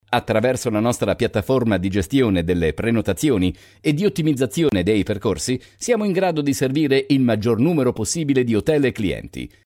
意大利语样音试听下载
意大利语配音员（男1）